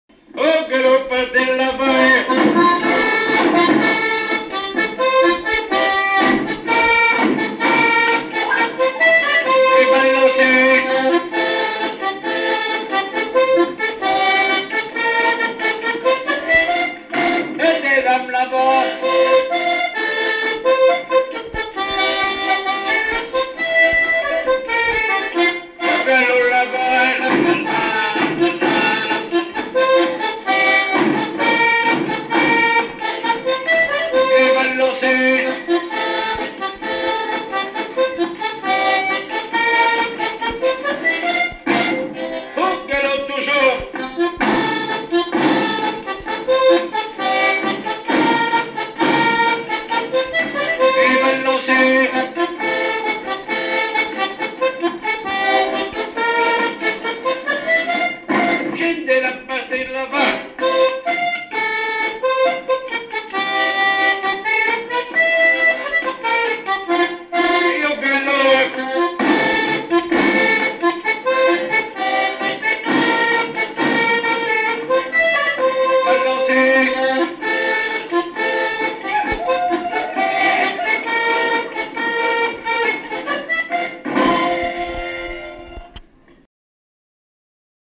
Mémoires et Patrimoines vivants - RaddO est une base de données d'archives iconographiques et sonores.
Quadrille - Galop
danse : quadrille : galop
Pièce musicale inédite